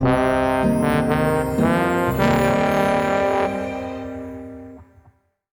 SC_Negative_Stinger_02.wav